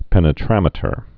(pĕnĭ-trămĭ-tər)